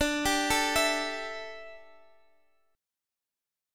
Listen to Dsus2sus4 strummed